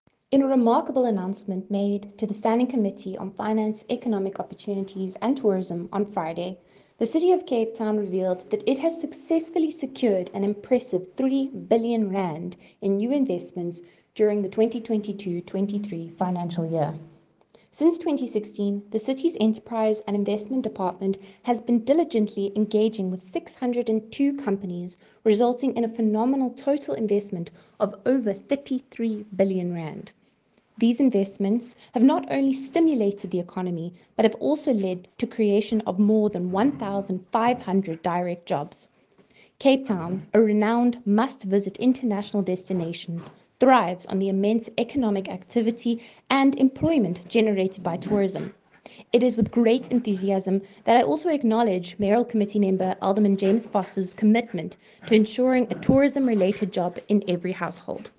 English soundbite from MPP Cayla Murray attached.